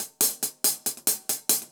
Index of /musicradar/ultimate-hihat-samples/140bpm
UHH_AcoustiHatC_140-01.wav